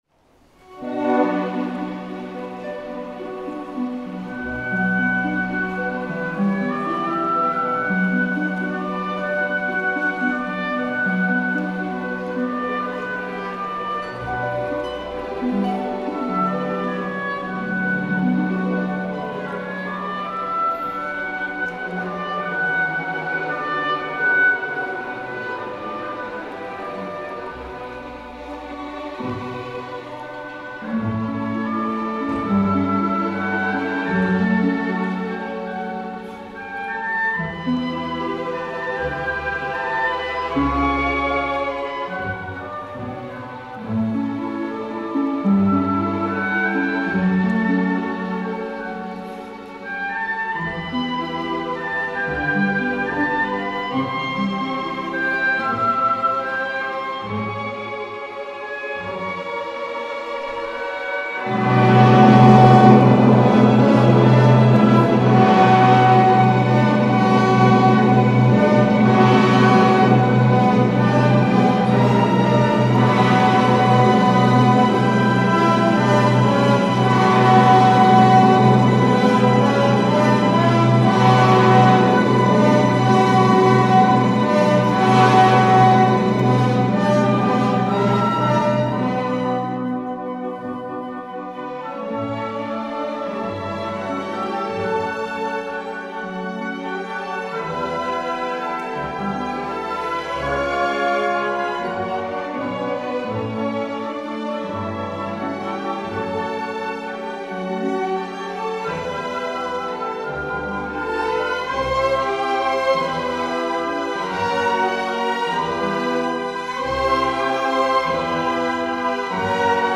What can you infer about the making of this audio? MP3 Intro Live